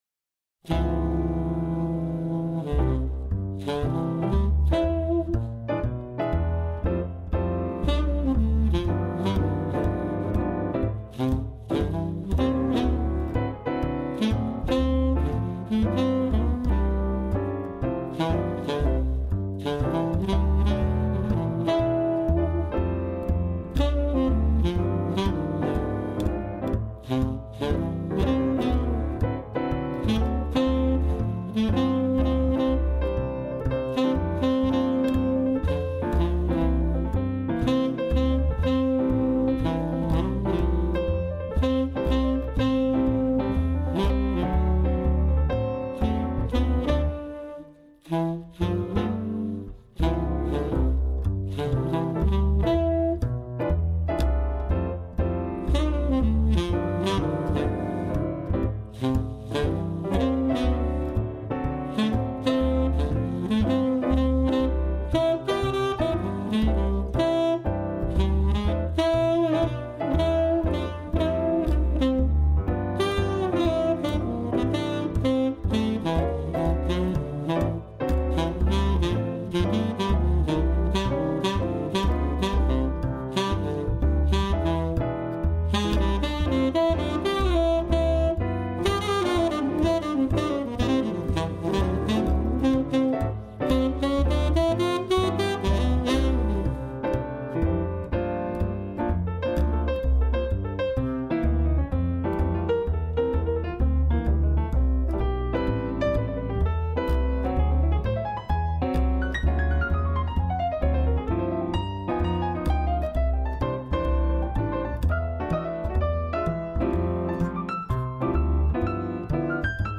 Groupe Jazz Mariage Marseille
Groupe Instrumental